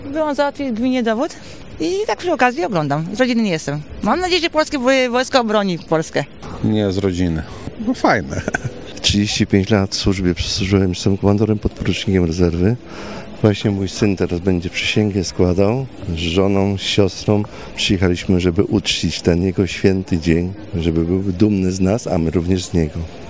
wojsko-sonda.mp3